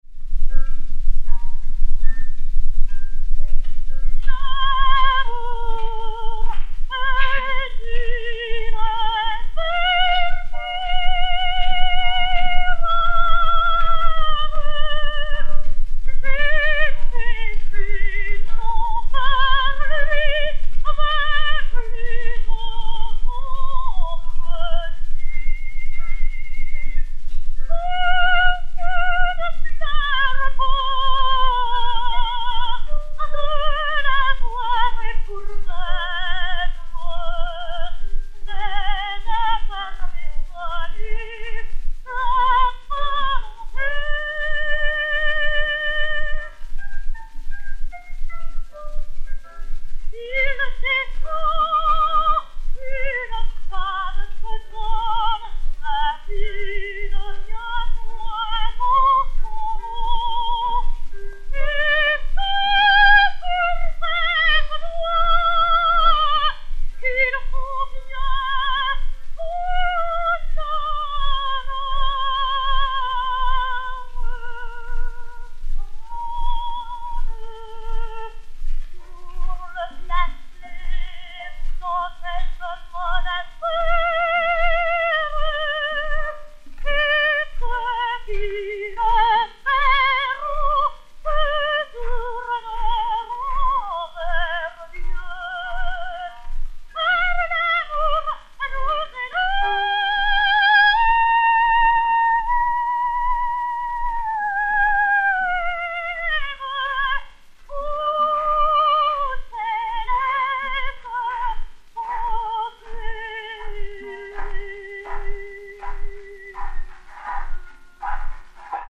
Fragment du Duo
Jane Merey (Juliette), Albert Vaguet (Roméo) et Piano